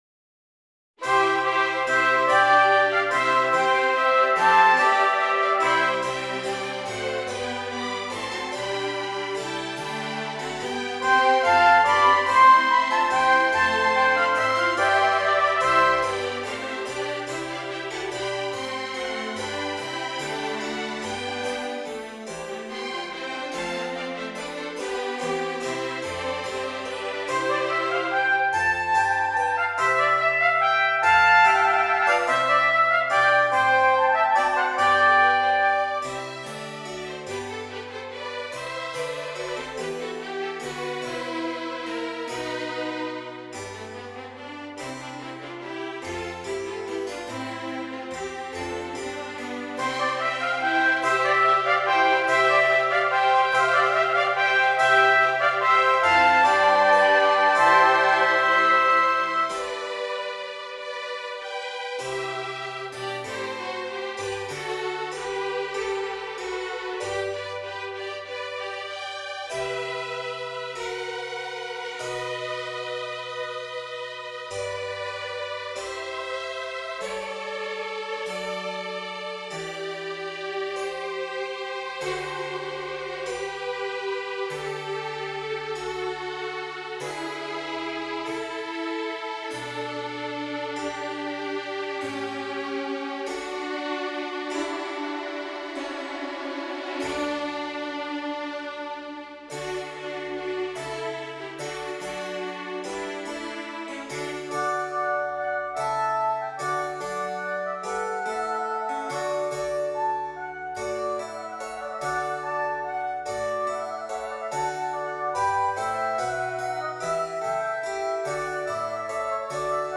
2 Trumpets and Strings